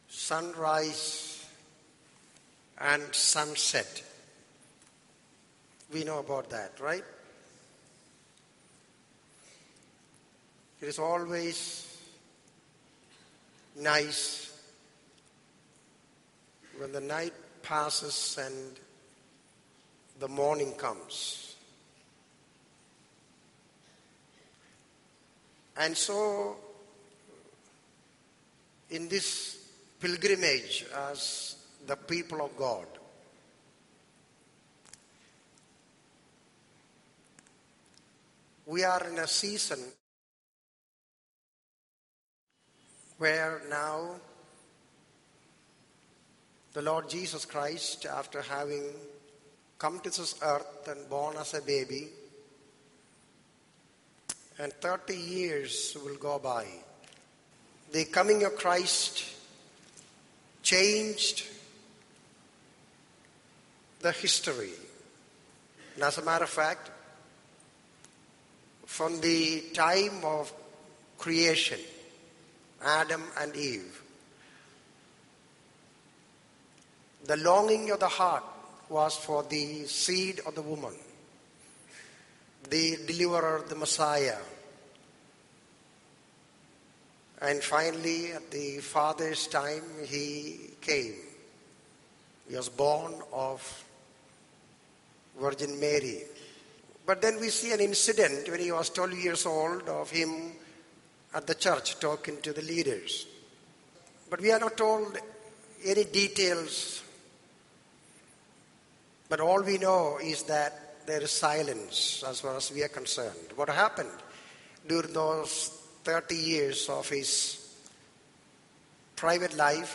In this sermon, the speaker emphasizes the importance of finding genuine peace and purpose in life. He highlights the sadness of lacking purpose and the temporary nature of accumulating wealth.